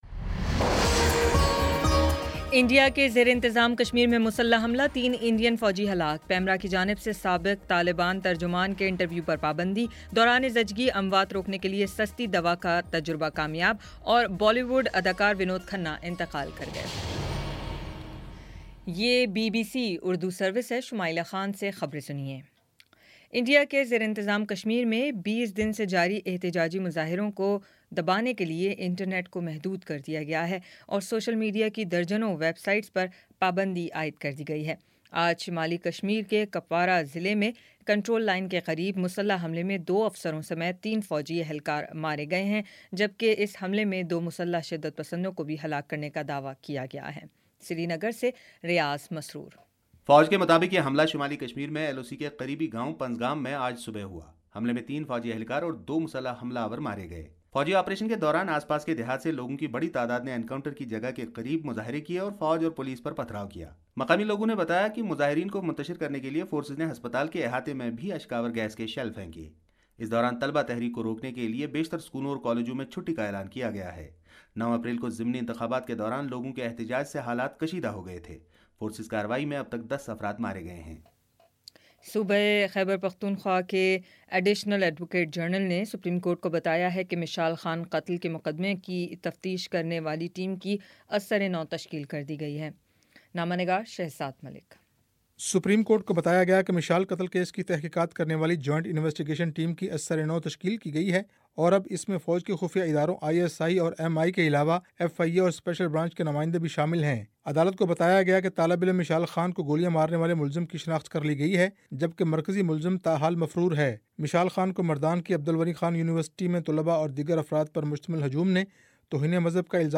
اپریل 27 : شام پانچ بجے کا نیوز بُلیٹن